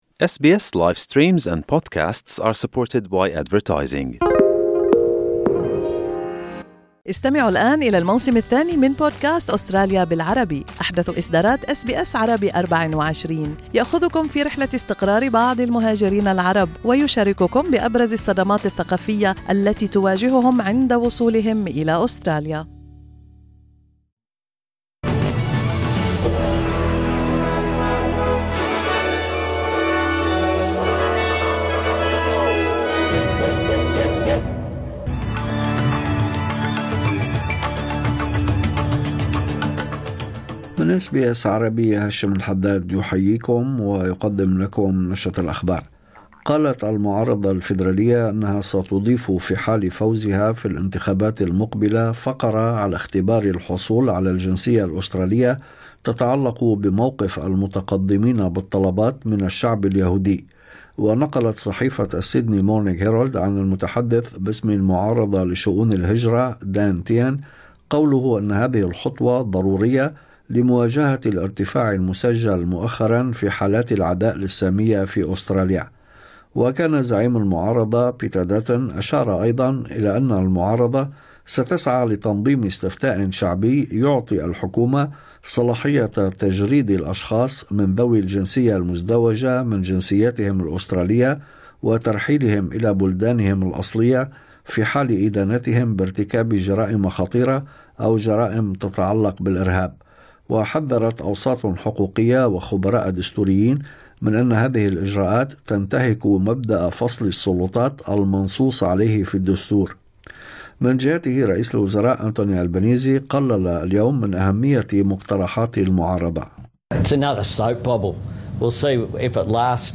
نشرة أخبار الظهيرة 19/3/2025